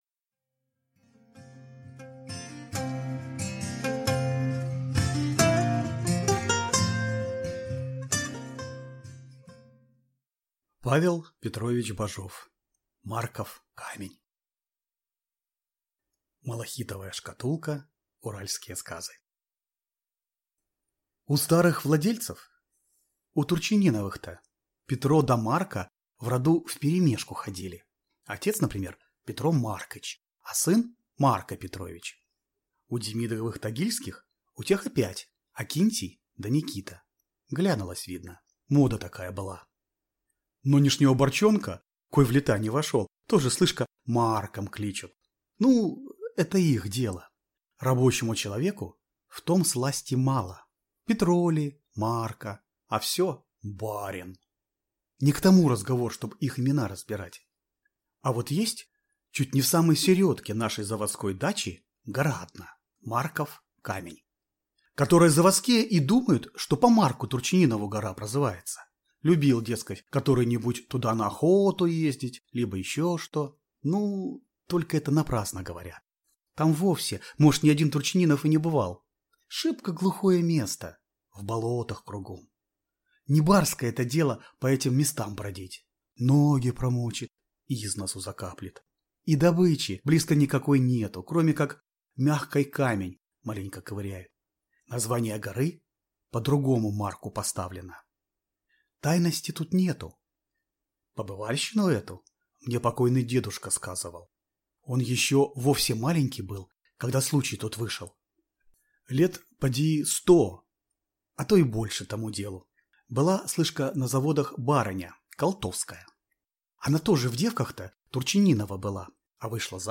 Аудиокнига Марков камень | Библиотека аудиокниг